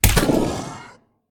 hell_dog4_get_hit3.ogg